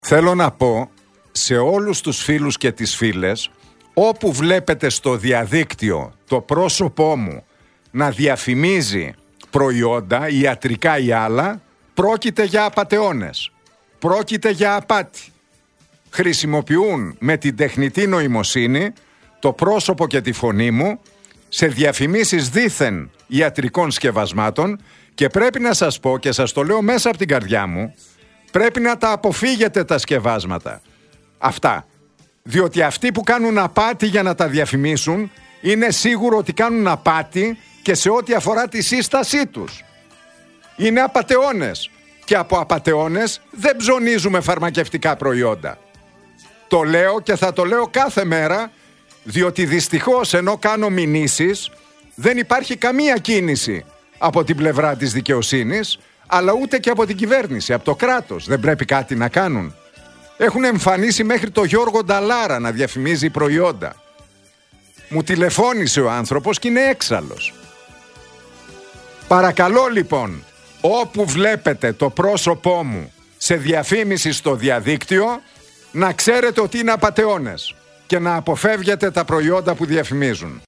Όπως είπε στην εκπομπή του στον Realfm 97,8, «όπου βλέπετε το πρόσωπό μου να διαφημίζει προϊόντα, ιατρικά και άλλα, πρόκειται για απατεώνες.